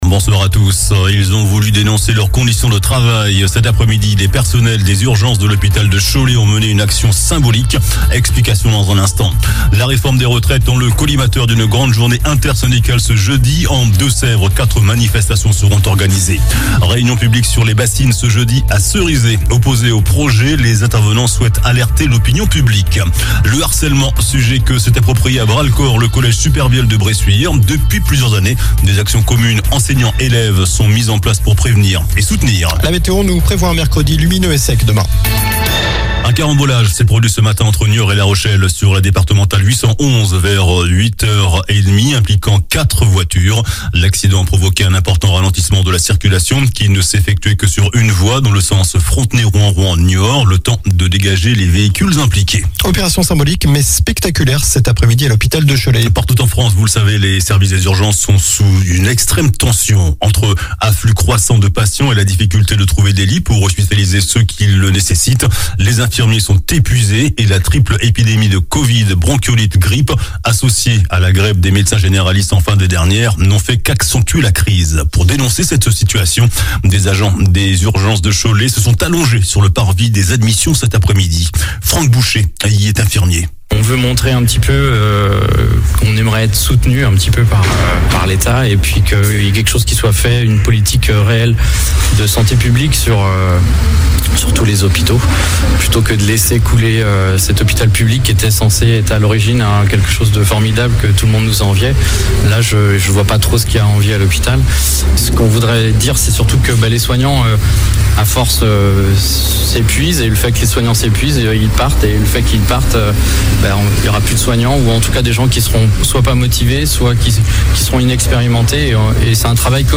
JOURNAL DU MARDI 17 JANVIER ( SOIR )